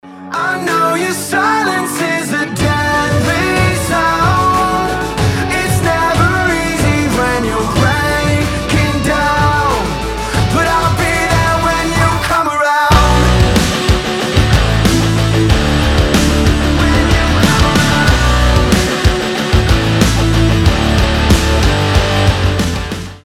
мужской вокал
громкие
Драйвовые
Alternative Rock